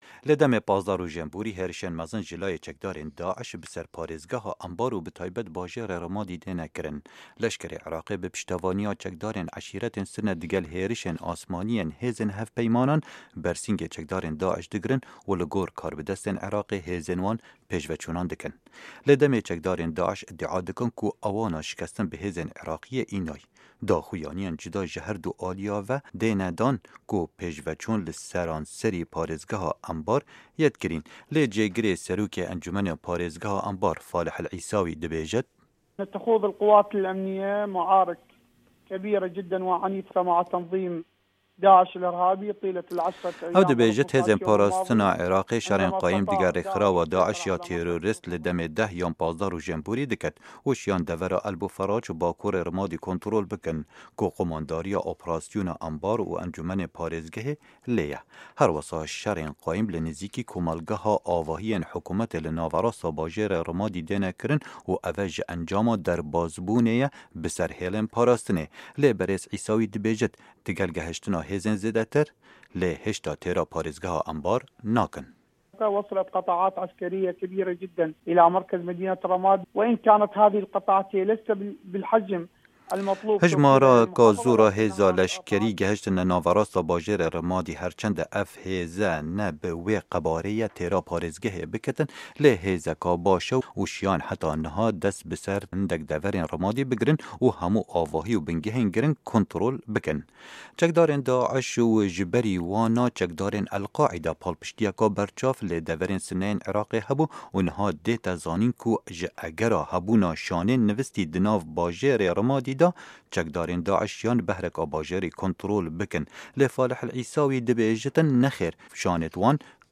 Hevpeyvin digel Falêh El Êsawî